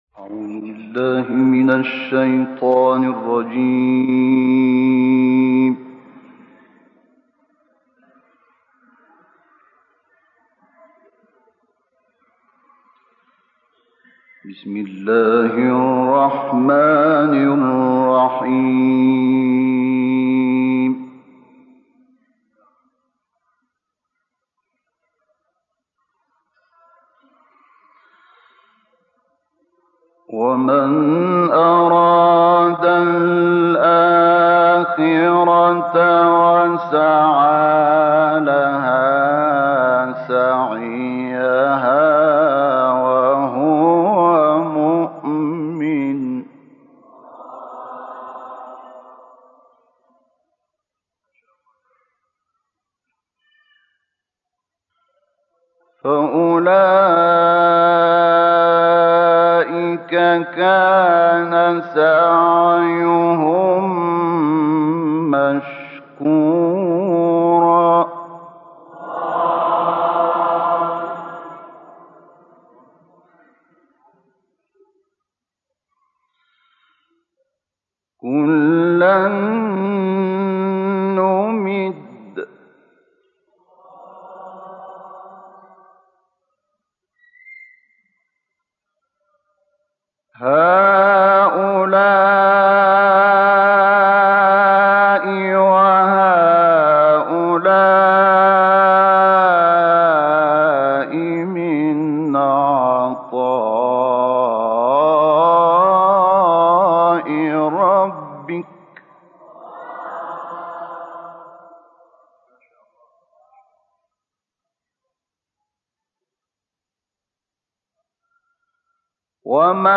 تلاوت آیاتی از سوره اسراء توسط مرحوم شحات محمد انور
تلاوت قرآن کريم